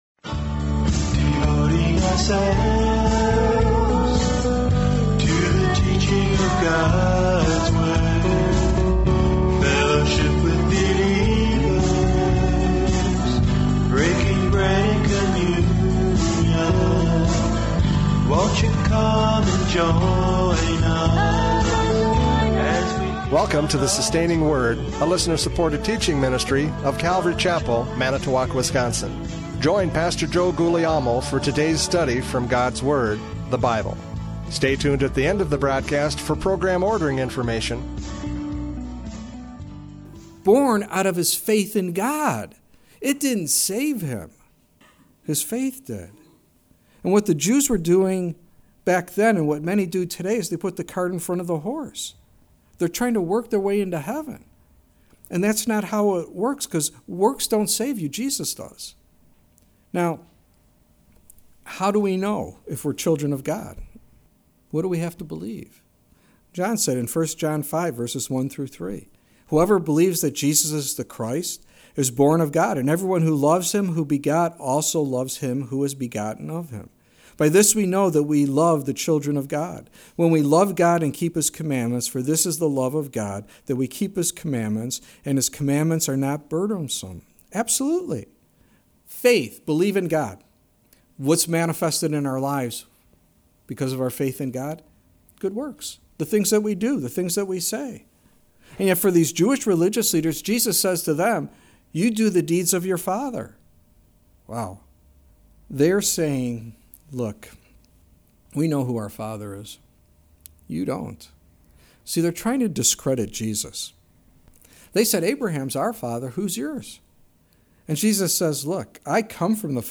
John 8:39-59 Service Type: Radio Programs « John 8:39-59 Physical or Spiritual Birth?